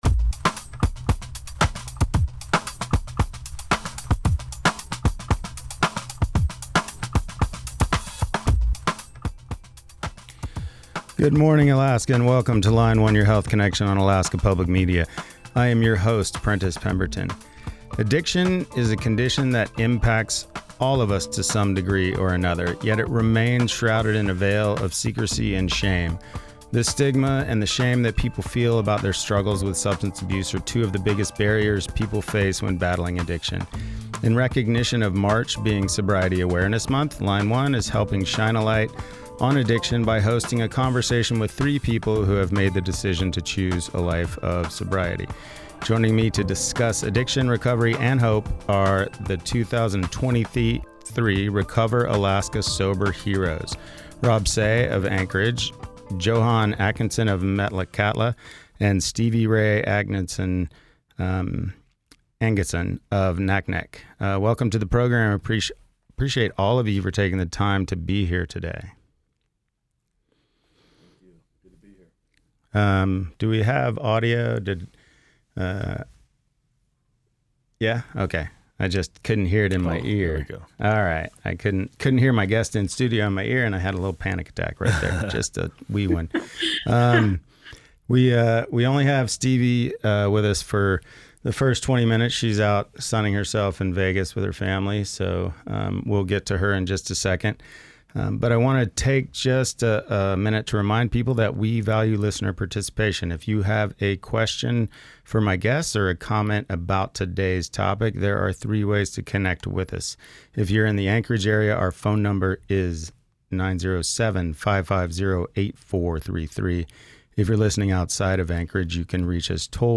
Line One features local physicians and national subject experts from the fields of child care, mental health, nutrition, pharmacology, surgery and more. Callers can talk one-on-one with each week’s guests and are encouraged to send in email questions as well.